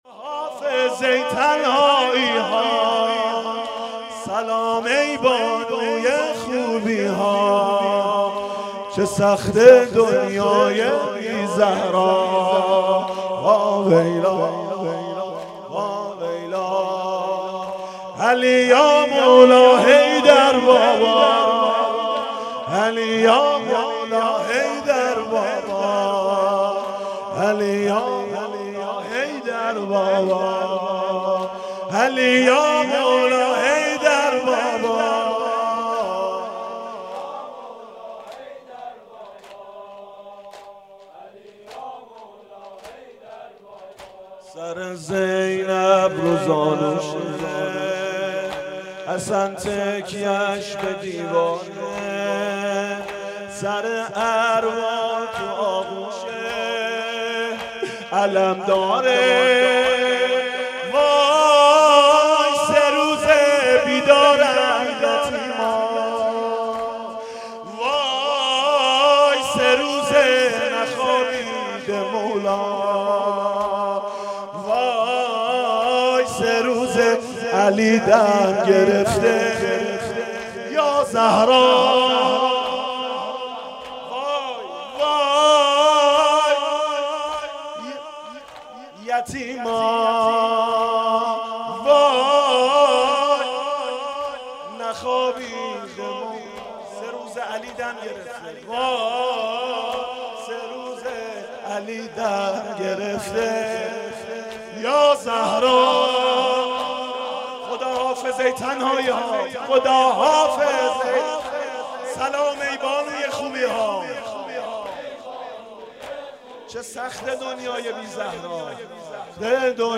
زمینه شب 21 رمضان 97
حسینیه بنی فاطمه(س)بیت الشهدا